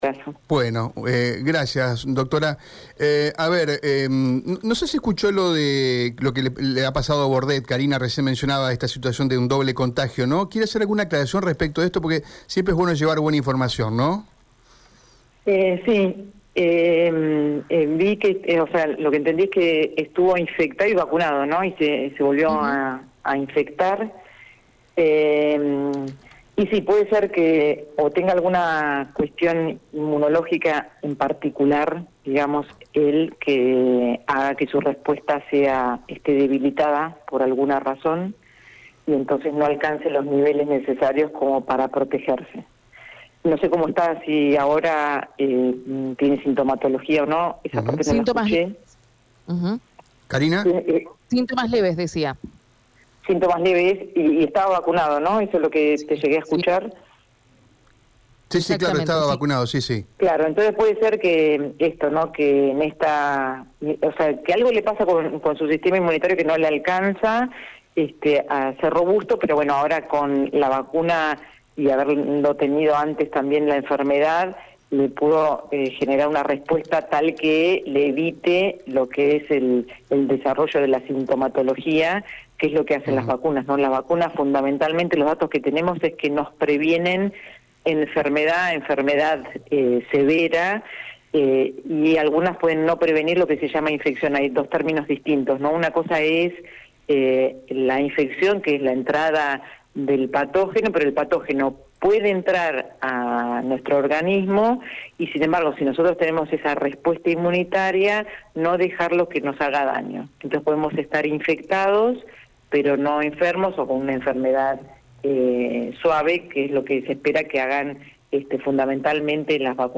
medica-del-conicet.mp3